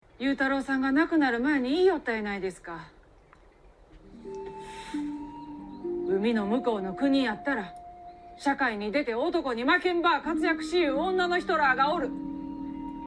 由於柳瀬小時候在四國的高知長大，因此本劇大部分角色皆使用當地方言——土佐方言。
底下聽小暢媽媽羽多子的錄音片段。